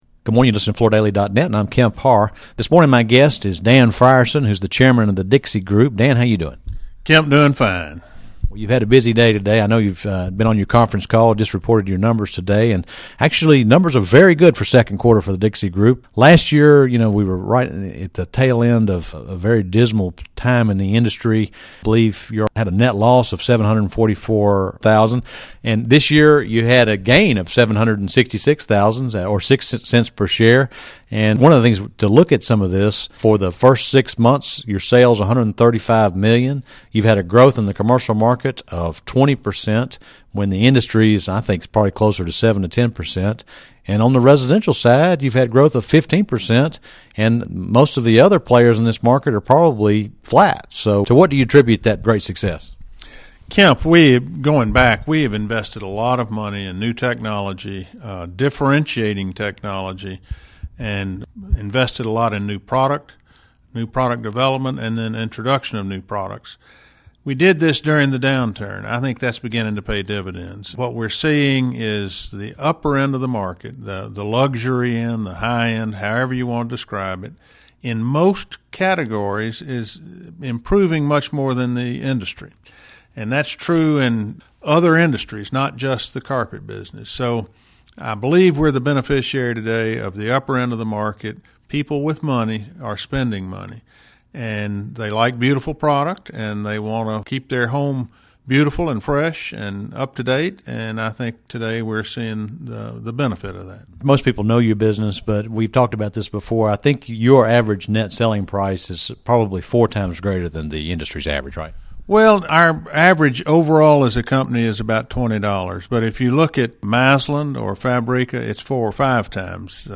Listen to the interview to hear how Dixie has been outpacing the industry in carpet sales in both the residential and commercial sectors.